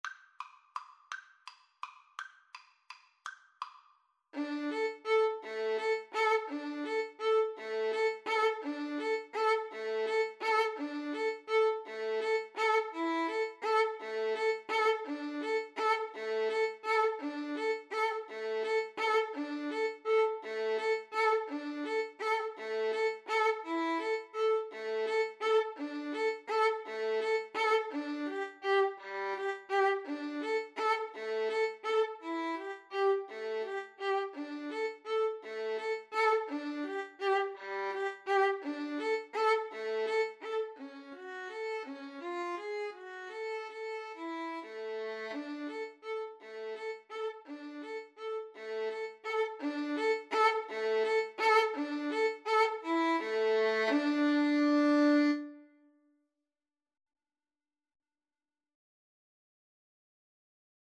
3/4 (View more 3/4 Music)
Tempo di valse =168
Classical (View more Classical Violin Duet Music)